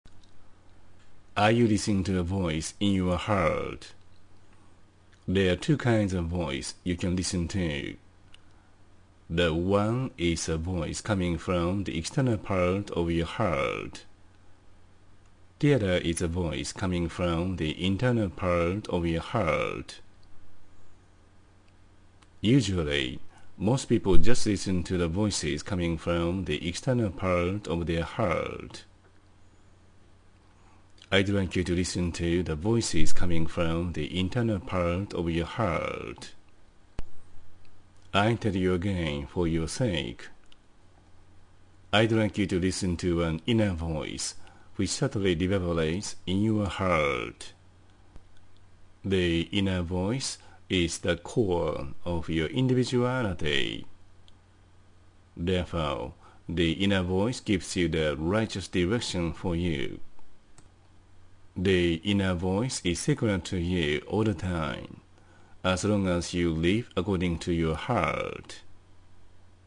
英語音声講義